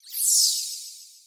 snd_dtrans_twinkle.ogg